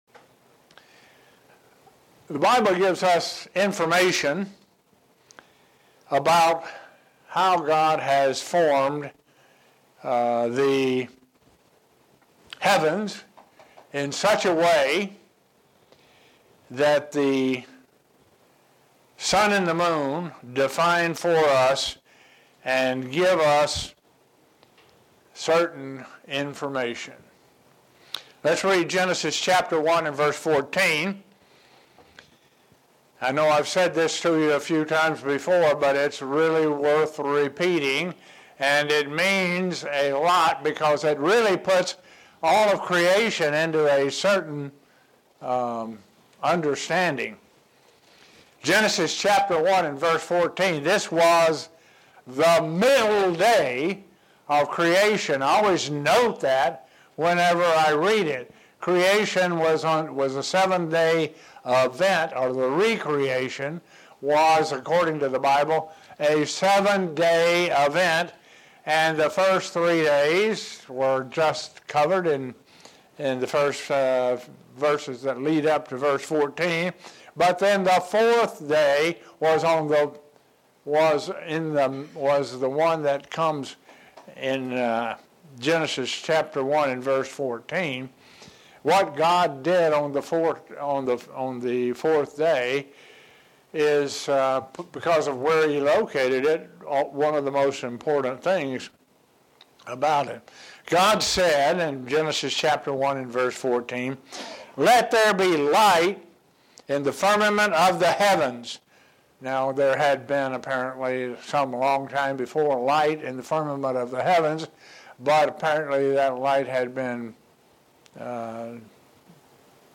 Sermons
Given in Buffalo, NY